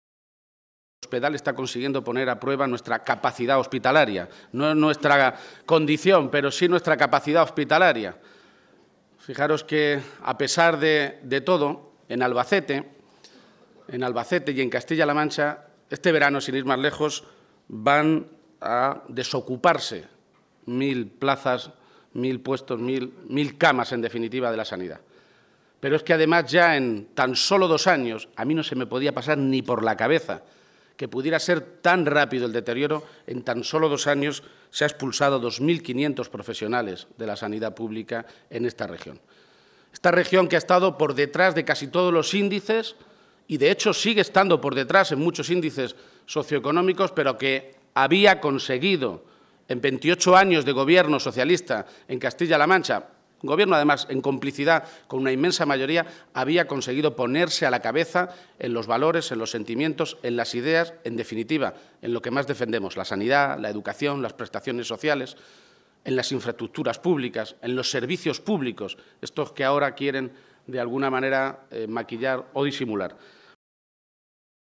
Page se pronunciaba de esta manera durante los Diálogos Ganarse el Futuro organizados a nivel nacional por el PSOE y que este domingo tenían a la ciudad de Albacete como sede para discutir sobre el modelo de Sanidad y servicios sociales.